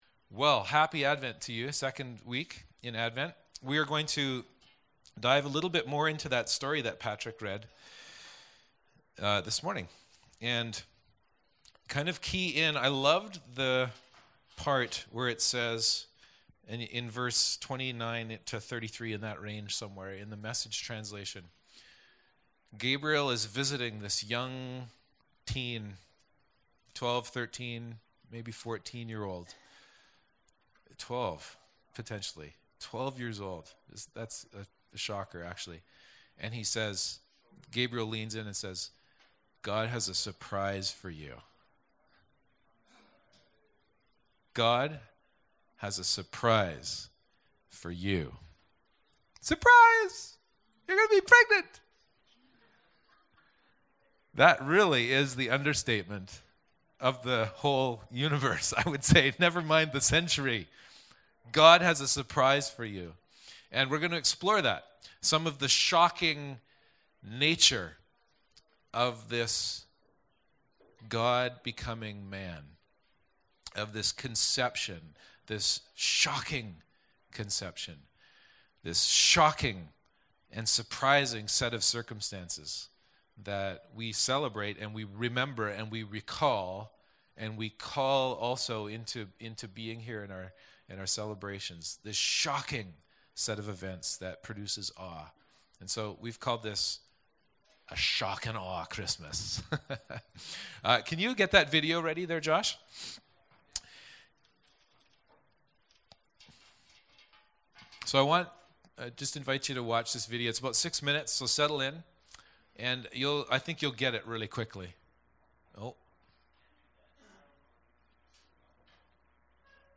Service Type: Downstairs Gathering